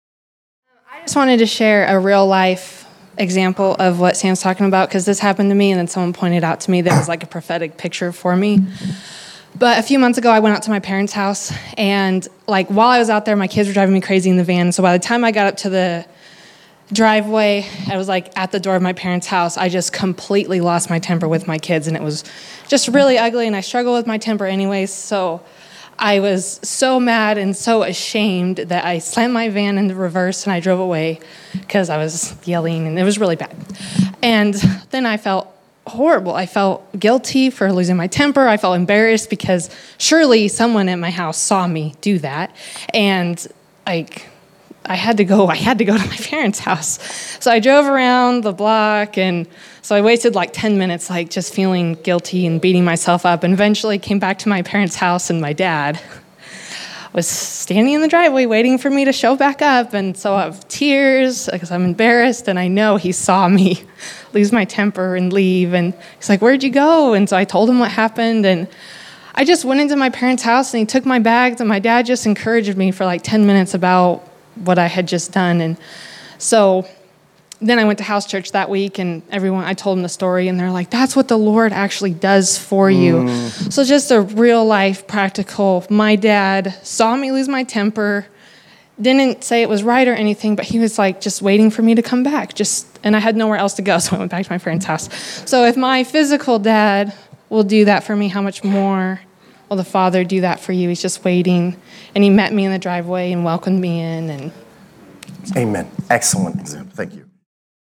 September 18, 2016      Category: Testimonies      |      Location: El Dorado